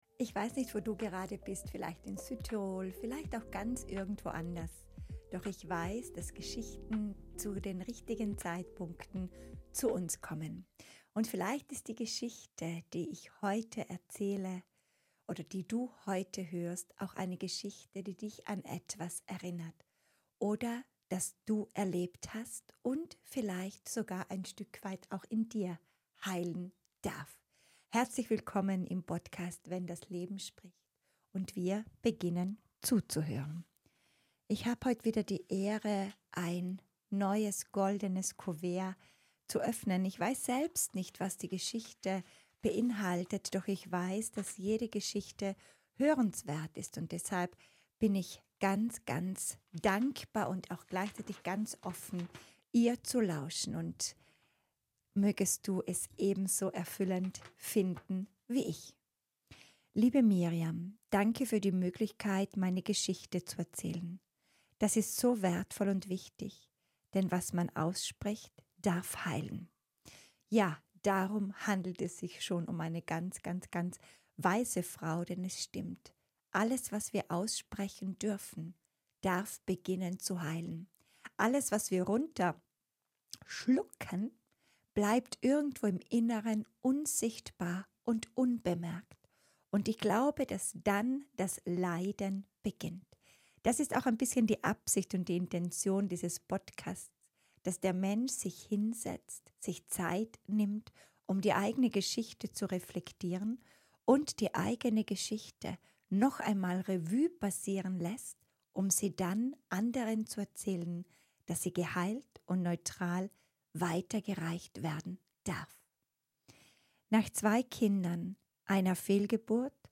Eine Frau teilt eine Geschichte, die unter die Haut geht. Eine Kindheit geprägt von Angst, Gewalt und Missbrauch.